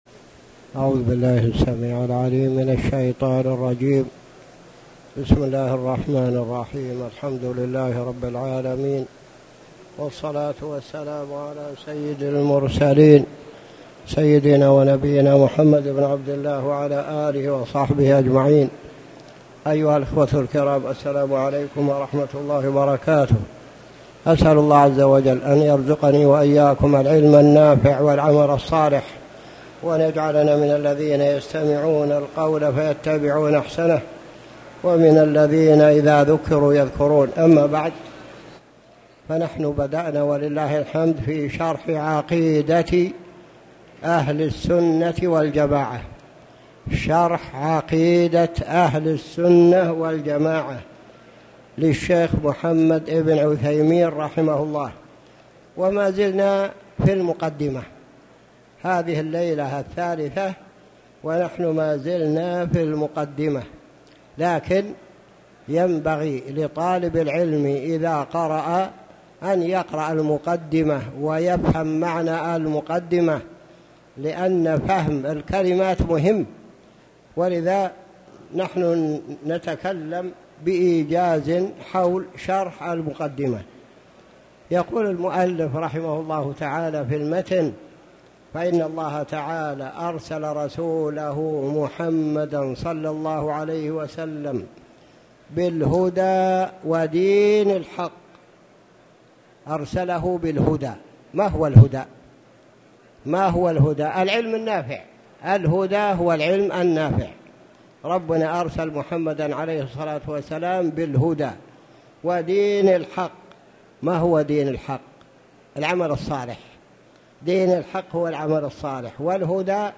تاريخ النشر ٧ شعبان ١٤٣٨ هـ المكان: المسجد الحرام الشيخ